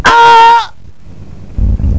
scream1.mp3